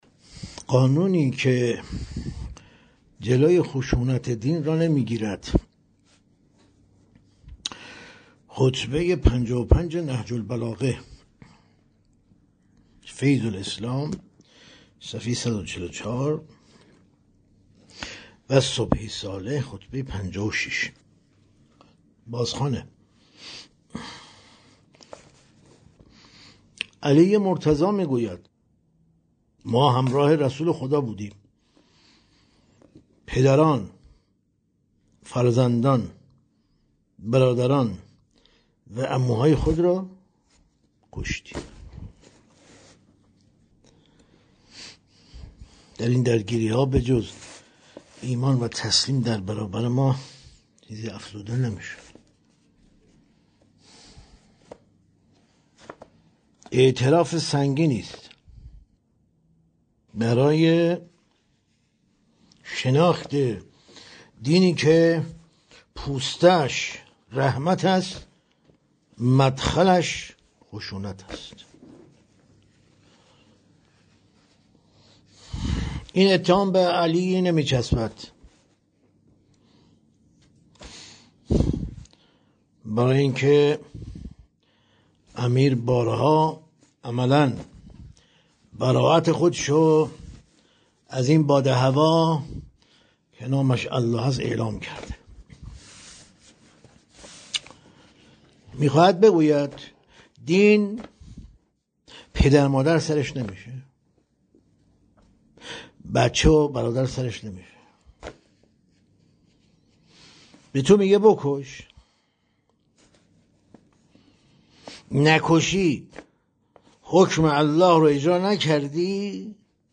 در این بخش، می‌توانید گزیده‌ای از تدریس‌های روزانه